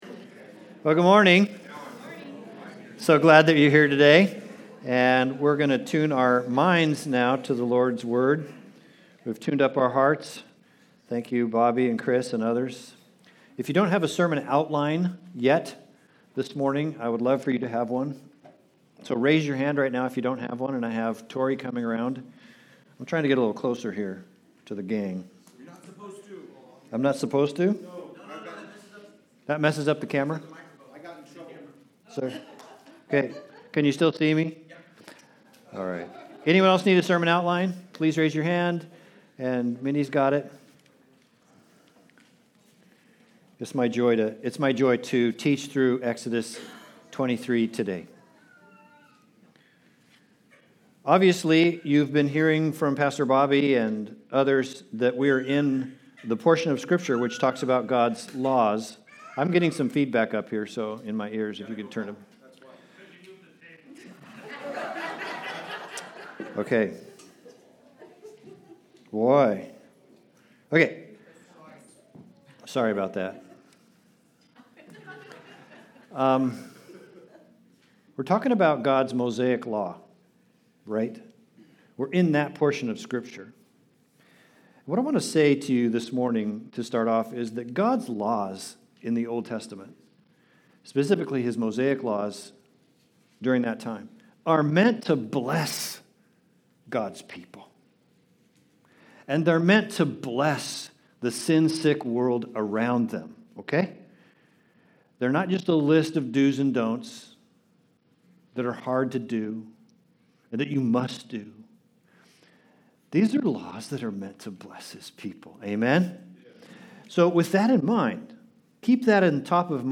20-33 Service Type: Sunday Service Related « Work